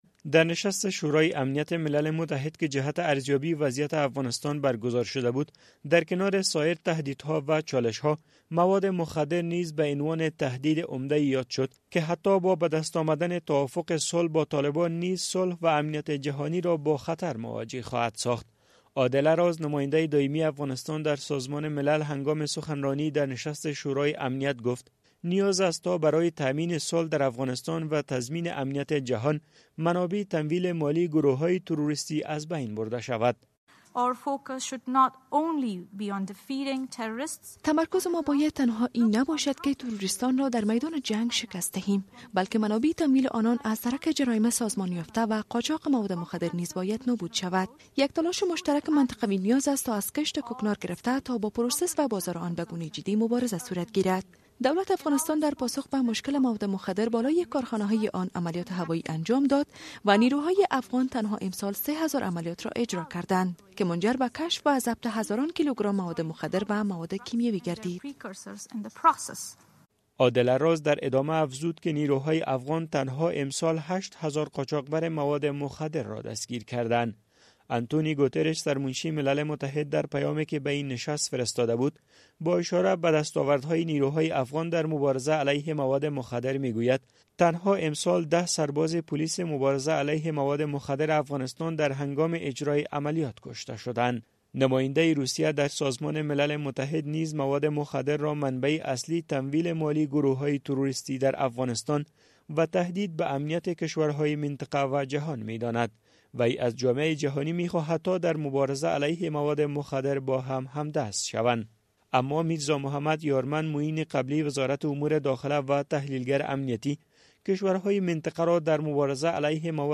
عادله راز نماینده دایمی افغانستان در سازمان ملل هنگام سخنرانی در نشست شورای امنیت گفت، نیاز است تا برای تامین صلح در افغانستان و تضمین امنیت جهان، منابع تمویل مالی گروه‌های تروریستی از بین برده شود.